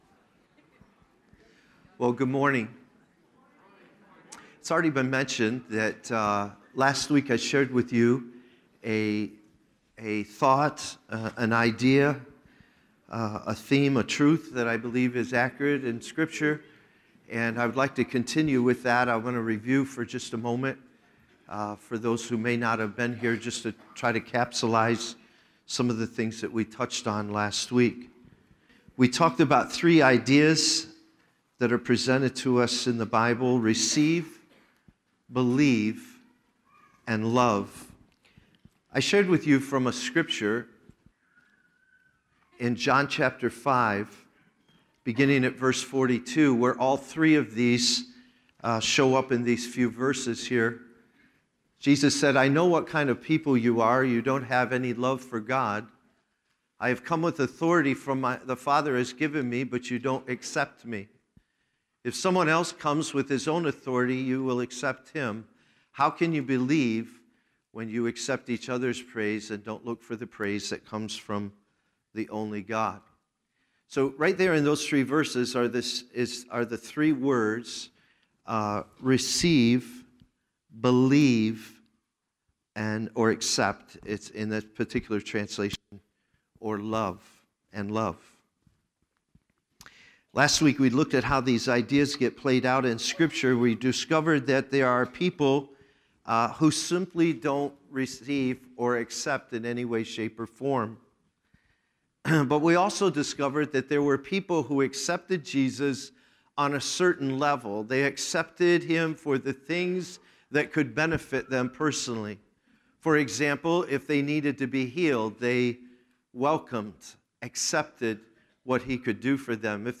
2 17 19 sermon.mp3